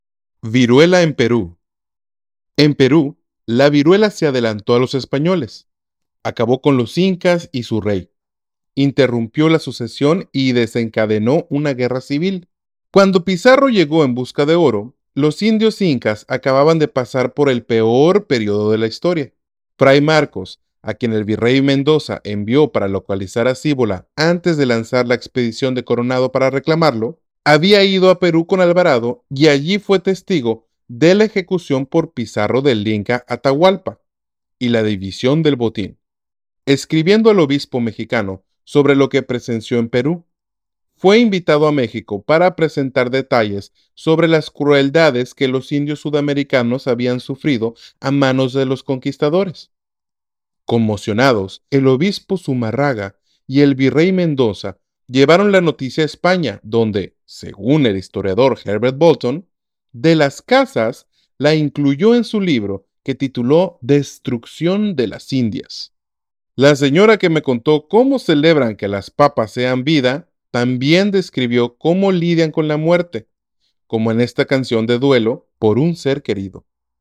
La señora que me contó cómo celebran que las papas sean vida también describió cómo lidian con la muerte, como en esta canción de duelo por un ser querido. The lady who told me about how they celebrate potatoes being life also described how they deal with death, like in this song of mourning for a loved one.